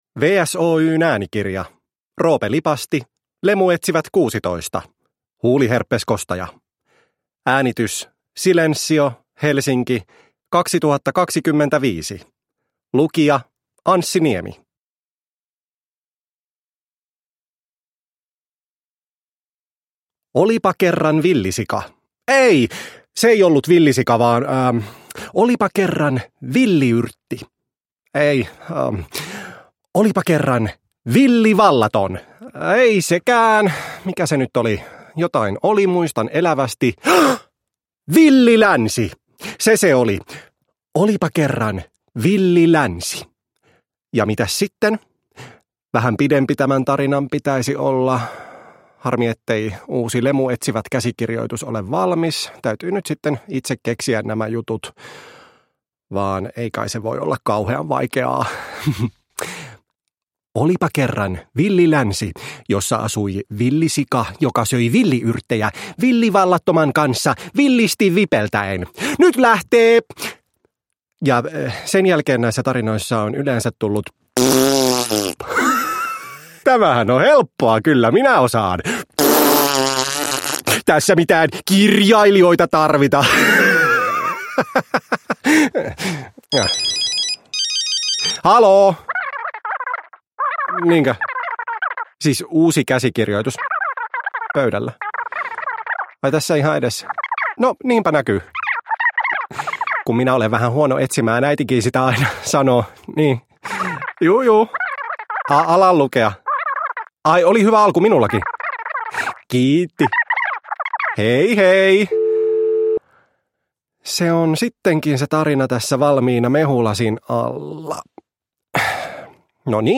Lemuetsivät 16: Huuliherpeskostaja (ljudbok